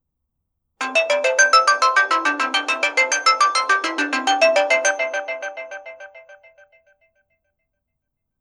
System-1 Memory Set 7: Sequencer Sounds